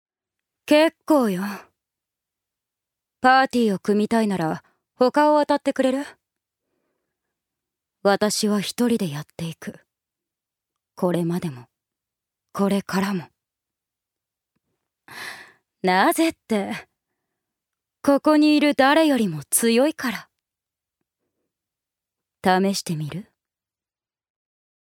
女性タレント
セリフ２